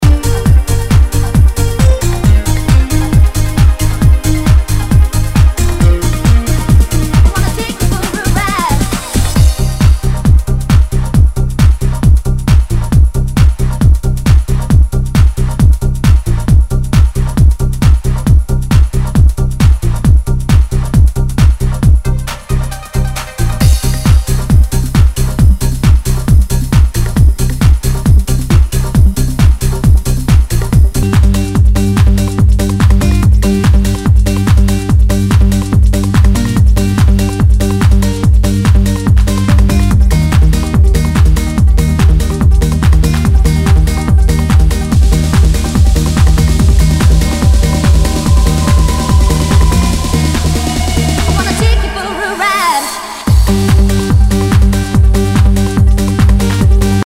HOUSE/TECHNO/ELECTRO
ナイス！トランス！！